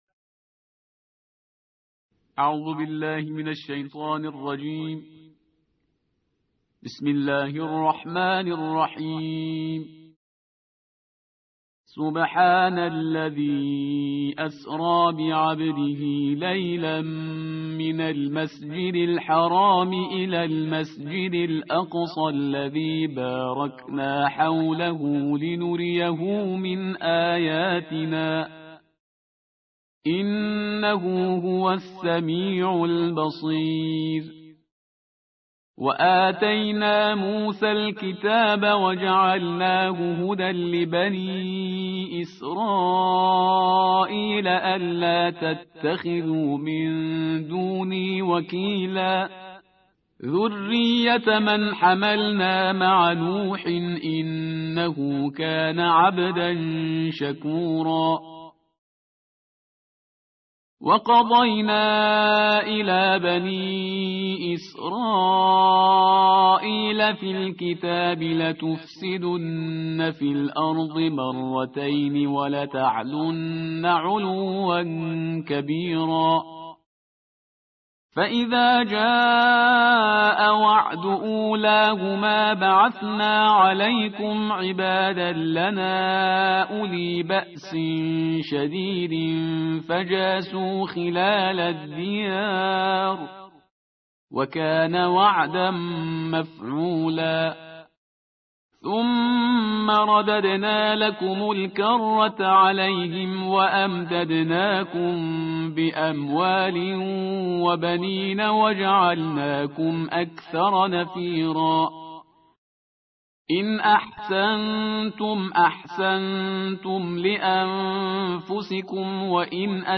ترتیل جزءپانزده قرآن کریم/استاد پرهیزگار